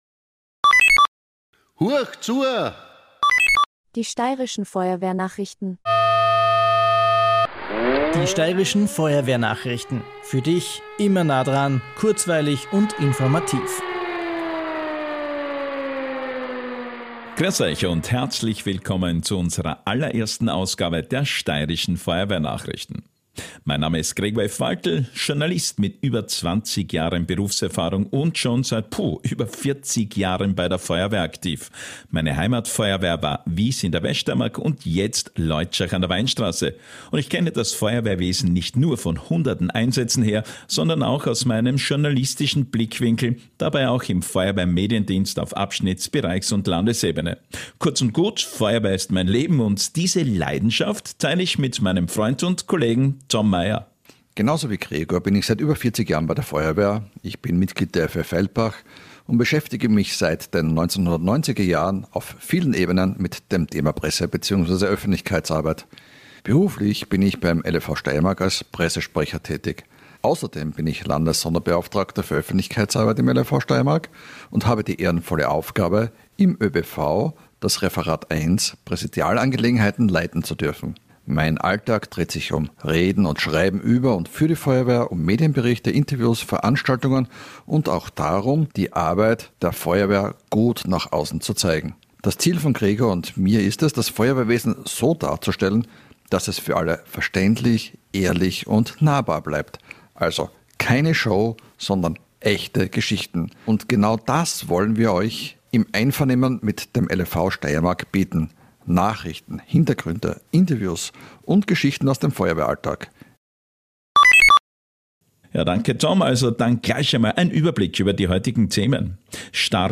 Genau dazu laden wir euch ein: Feuerwehr-Themen aus der Steiermark und für die Steiermark – informativ, kompakt und im Stil einer Nachrichtensendung.